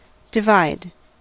divide: Wikimedia Commons US English Pronunciations
En-us-divide.WAV